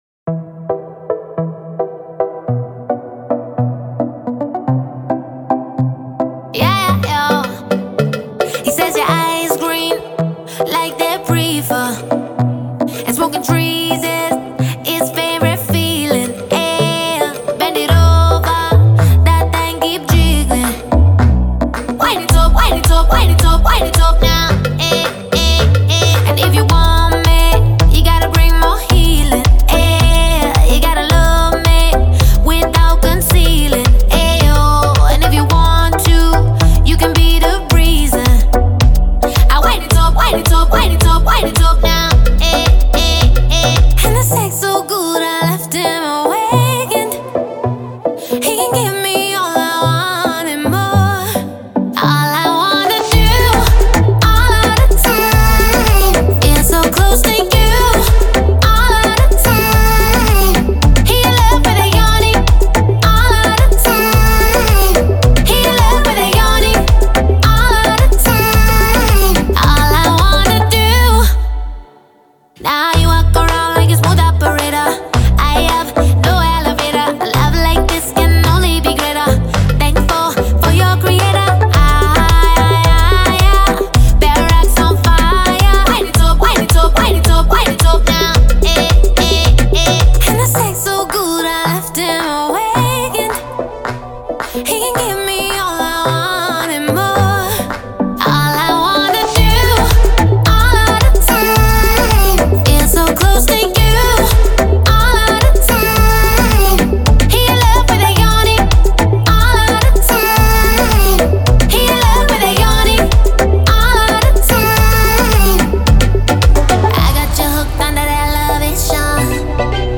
это яркая и мелодичная композиция в жанре латин-поп